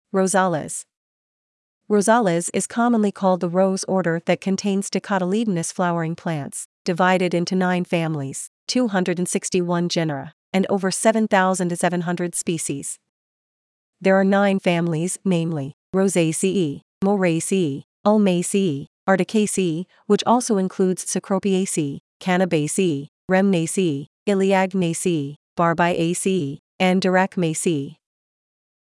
Rosales Pronunciation
Rosales-Pronunciation.mp3